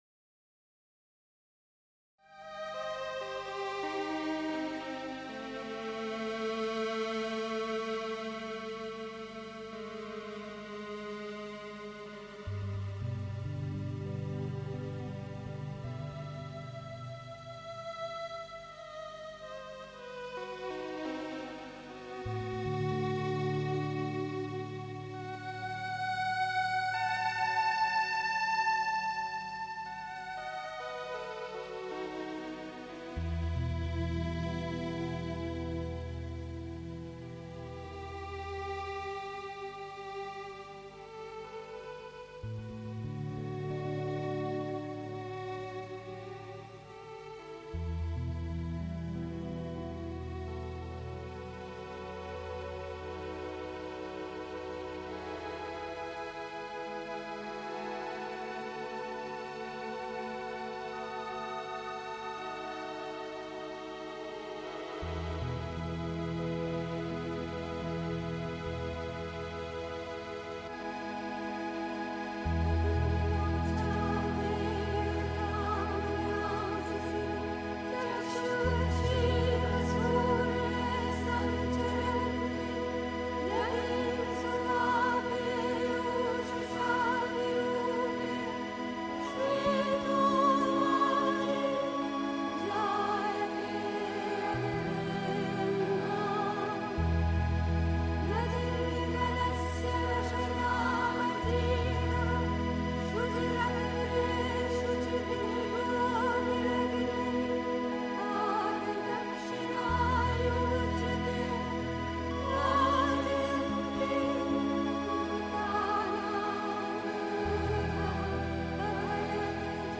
Um gegen alle Furcht, Gefahr und alles Unheil gewappnet durchs Leben zu gehen (Sri Aurobindo, CWSA Vol 32, pp. 8-9) 3. Zwölf Minuten Stille.